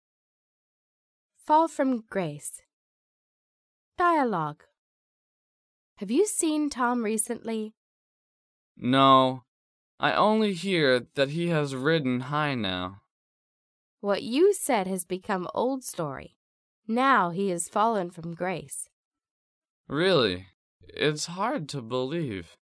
迷你对话：